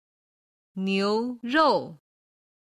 今回のBBQで使える中国語単語音源
牛肉は「牛肉(niú ròu)」